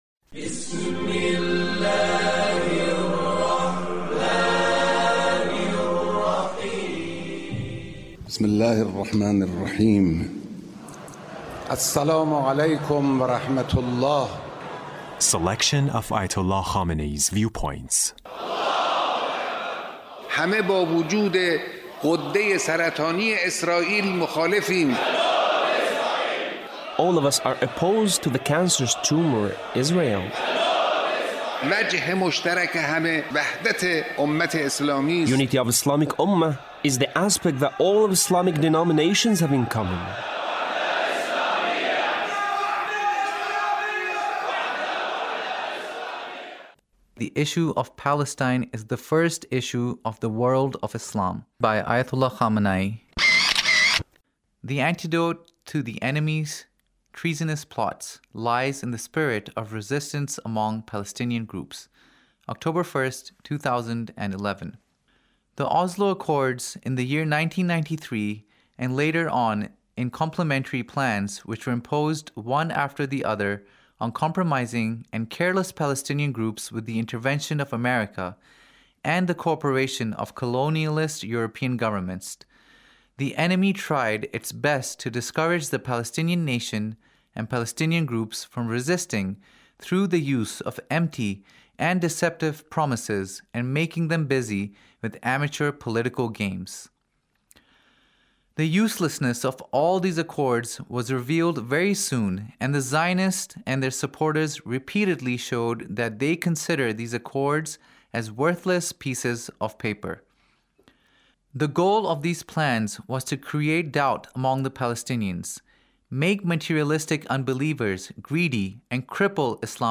Leader's Speech about Palestine